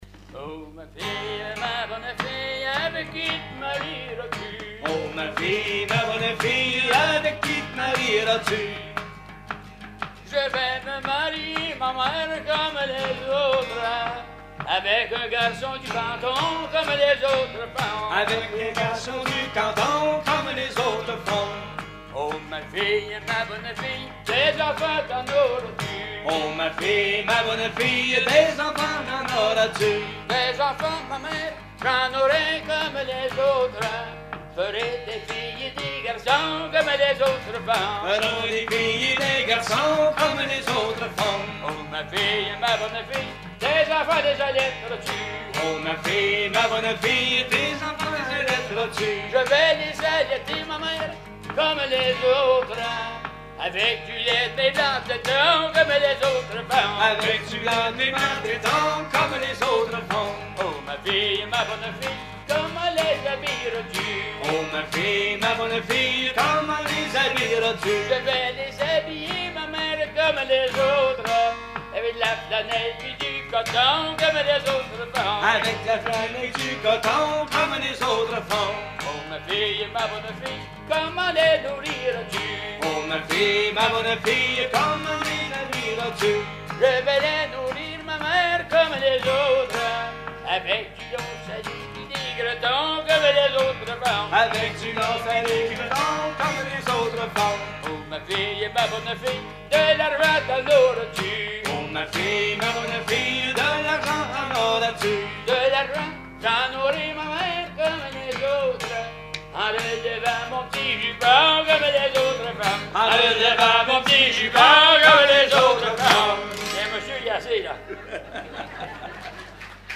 Genre dialogue
Concert à la ferme du Vasais
Pièce musicale inédite